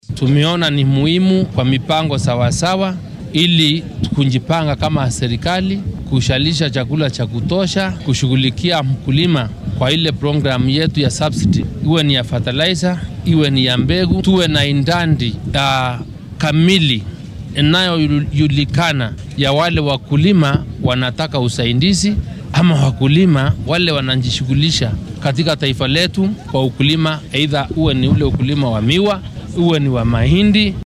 Dowladda dhexe ayaa billowday tababar la siinaya saraakiil diiwaangelin doono beeralayda wadanka. Waxaa barnaamijkan ka hadlay wasiirka beeraha , Mithika